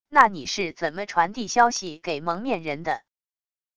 那你是怎么传递消息给蒙面人的wav音频生成系统WAV Audio Player